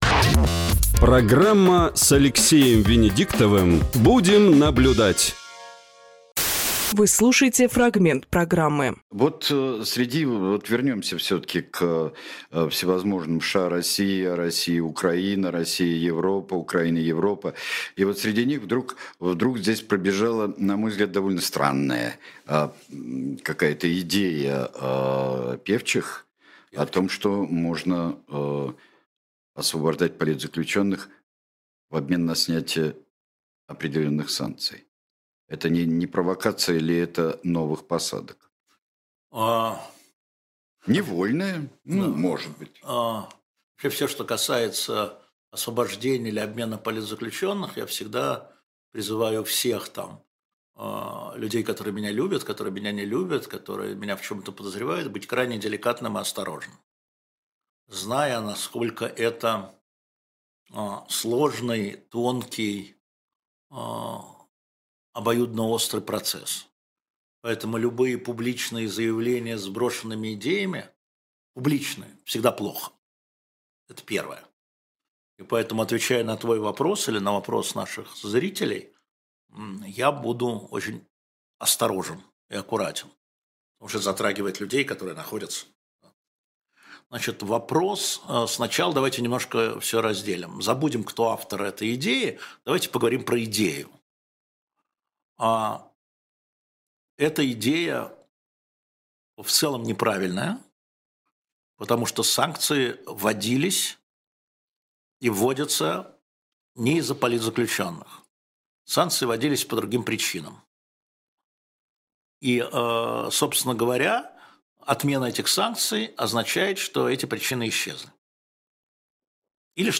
Фрагмент программы от 06.01,2025